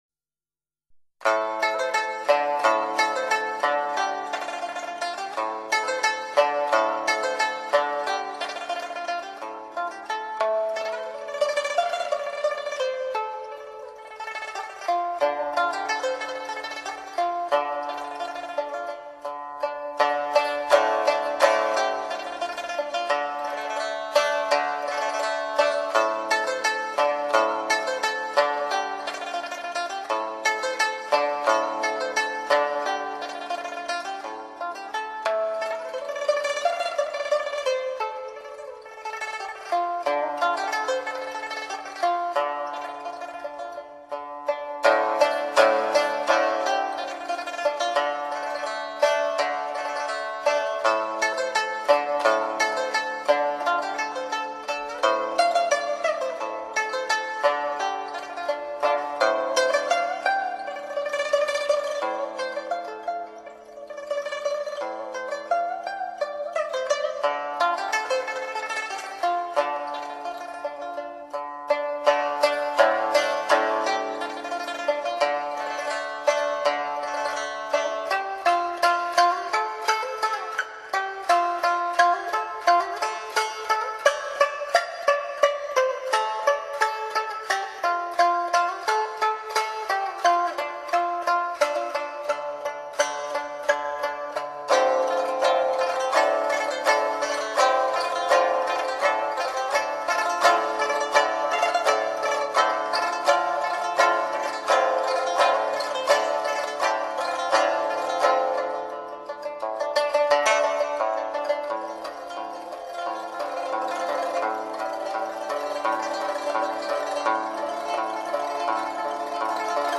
【民樂】
這是一張抒情雋永，輕鬆悠揚的專輯。
琵琶